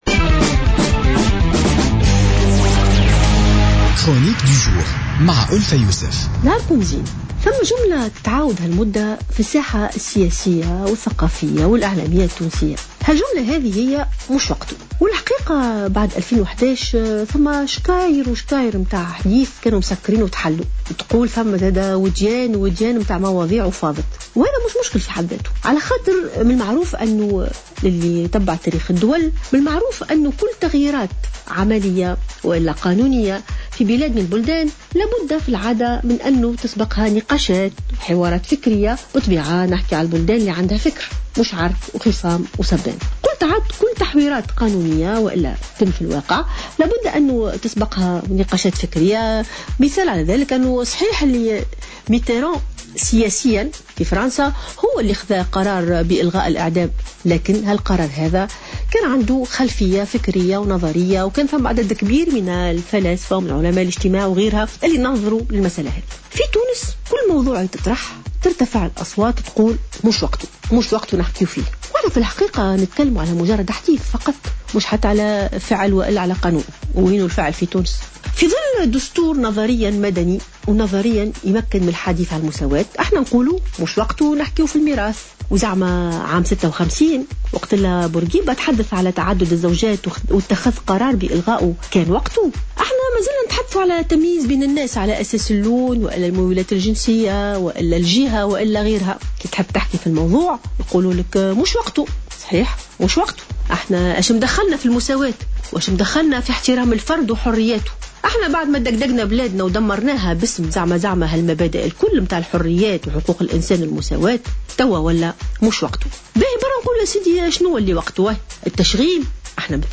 قالت المفكرة والجامعية ألفة يوسف في افتتاحية اليوم الأربعاء إنه كلما تم التطرّق لأي ملف أو موضوع من المواضيع التي تهم الشأن العام في تونس إلا و تعالت عديد الأصوات قائلة "موش وقتو".